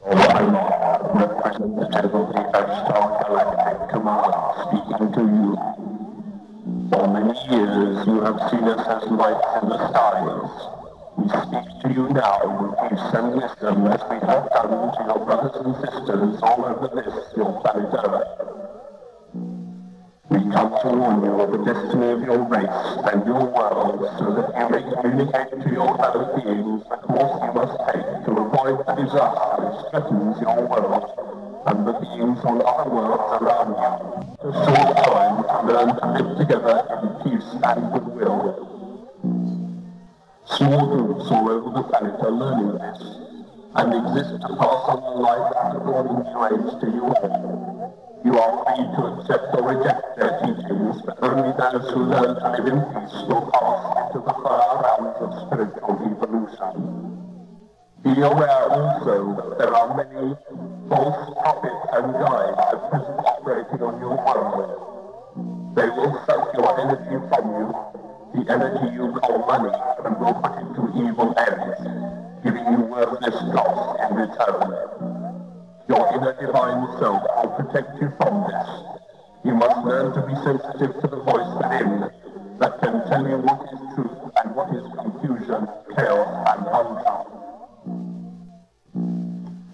A T.V. station was interrupted and this very strange broadcast was heard by many. Could it be an alien message?